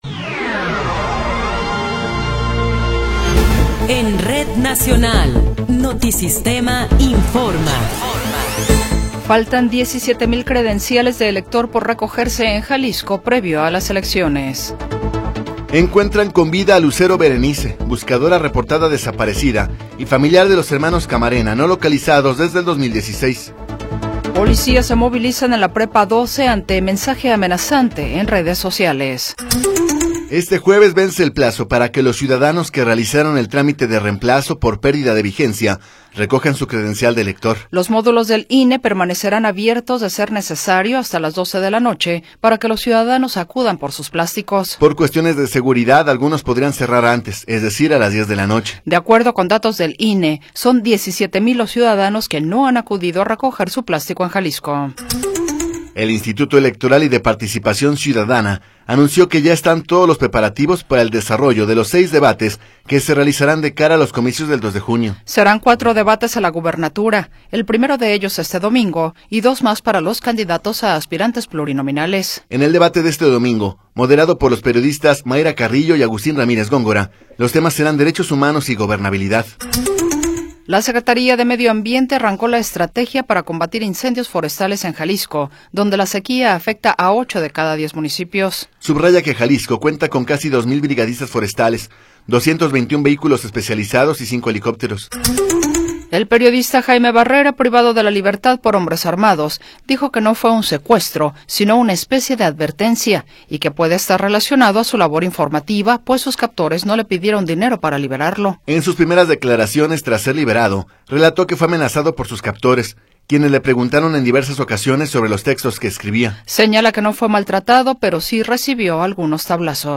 Noticiero 20 hrs. – 13 de Marzo de 2024
Resumen informativo Notisistema, la mejor y más completa información cada hora en la hora.